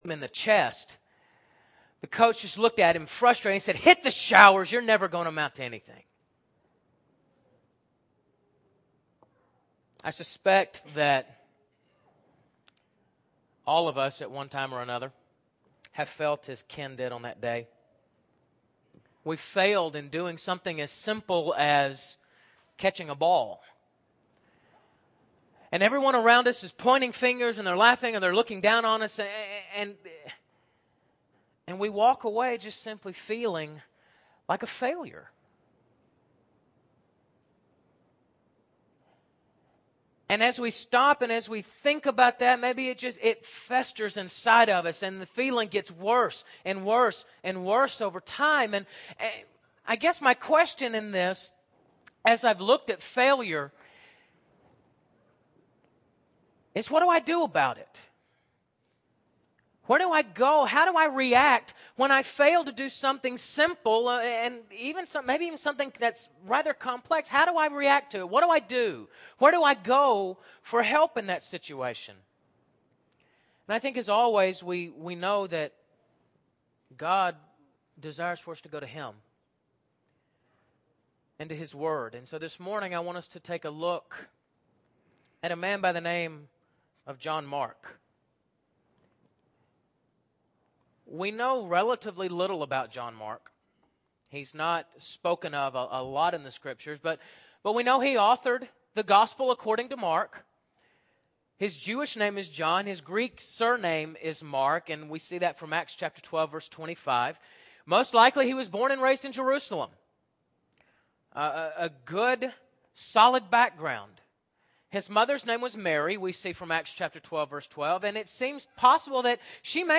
John Mark – Bible Lesson Recording